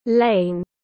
Ngõ tiếng anh gọi là lane, phiên âm tiếng anh đọc là /leɪn/.